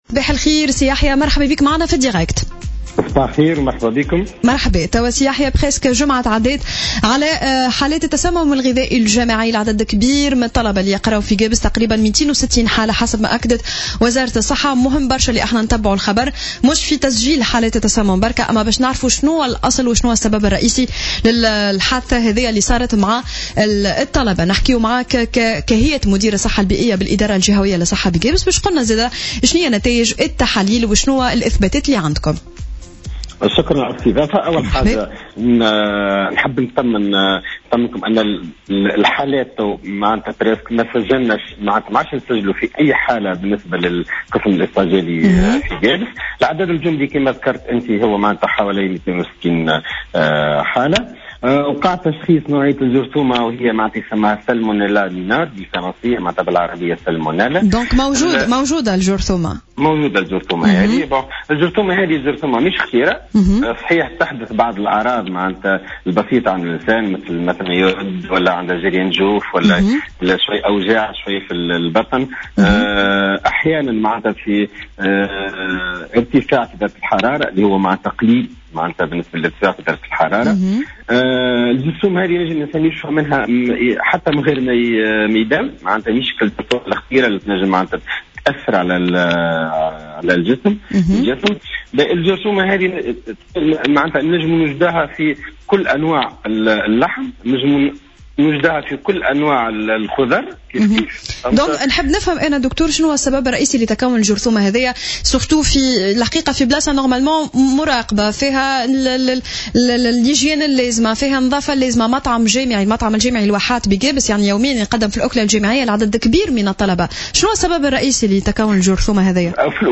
Lors de son intervention dans l'émission Sbeh El ward ce mardi 14 mars 2017